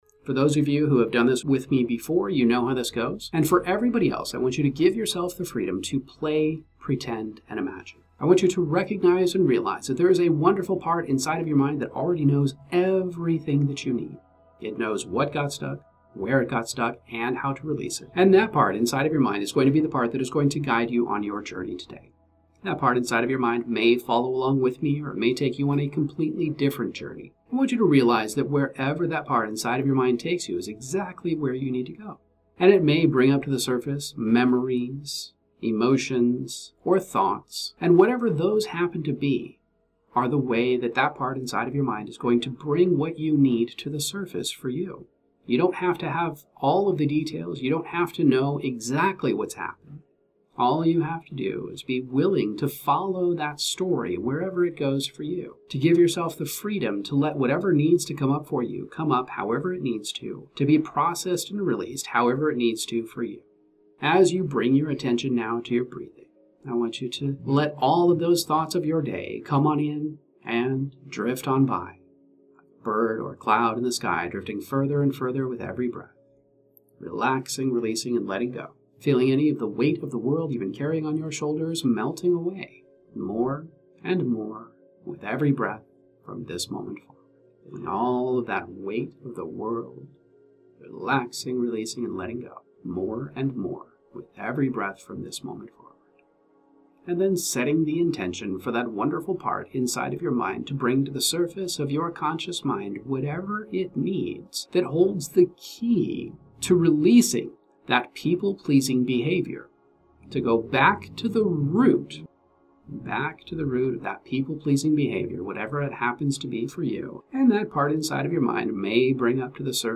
This guided hypnosis meditation is like a magical journey from feeling stuck in a cycle of trying to make everyone else happy to discovering what it means to be truly you. It starts with chilling out and letting go of the day's stress, then diving deep into your mind to uncover the roots of that people-pleasing habit.
Emotional Optimization™ Meditations